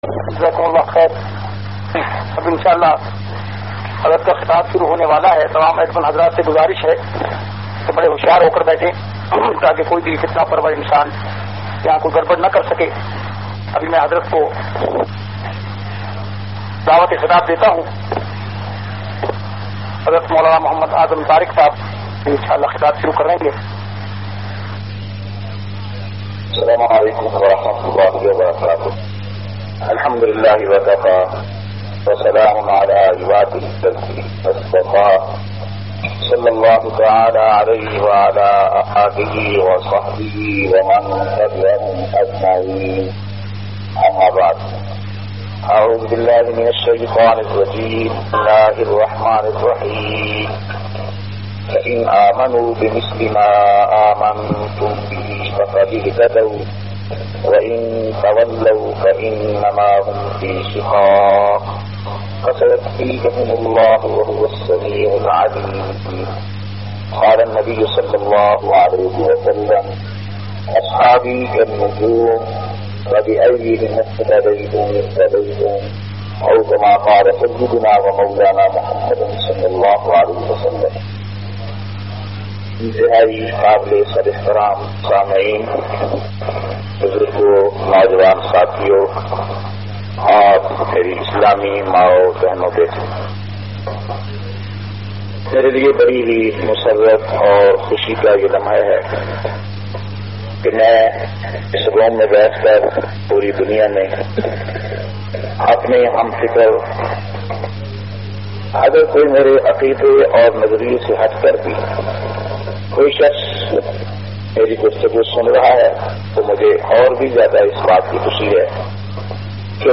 153- Maqam e Ashab e Rasool o Sawal jawab Internet py khitab.mp3